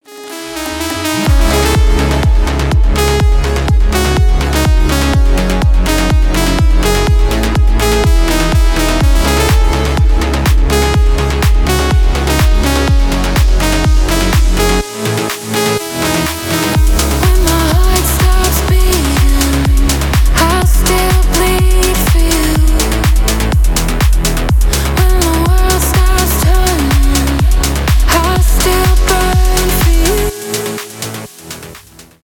громкие
progressive house , клубные
electronic